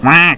quack.wav